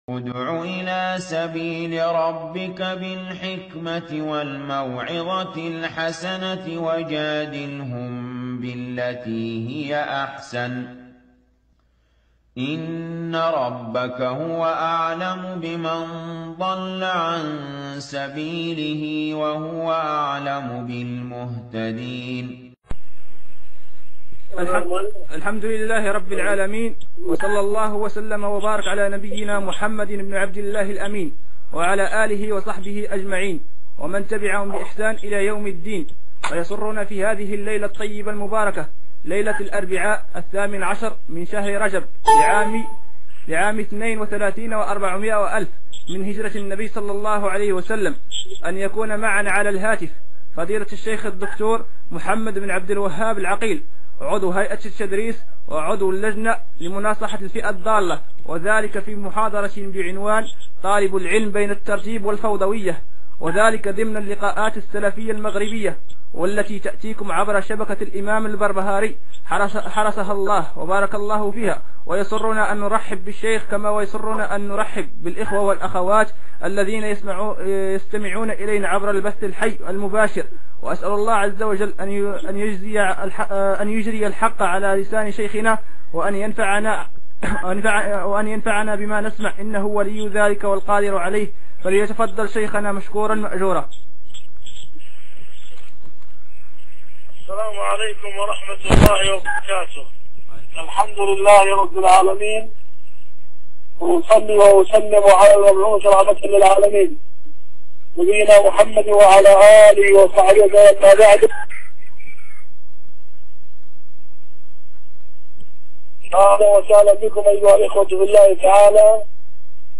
كلمة - طالب العلم بين الترتيب والفوضوية